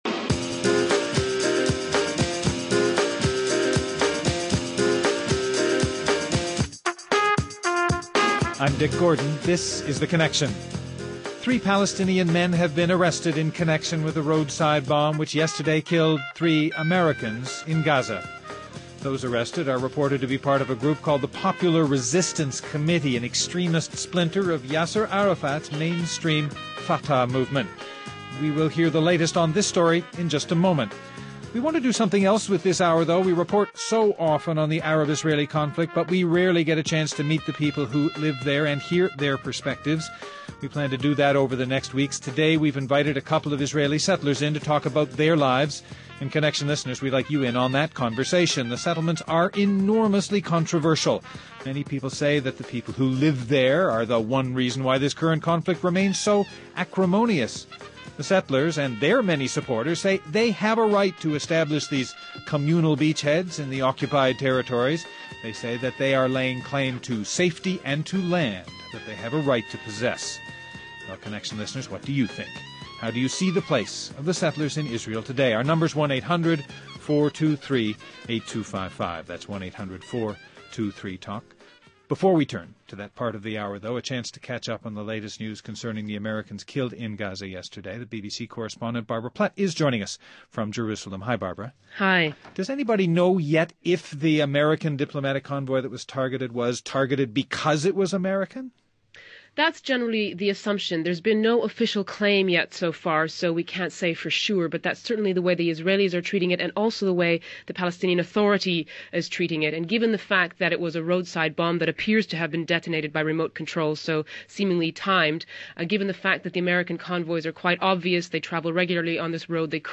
Today, we’ll speak with Jewish settlers who are living in the West Bank.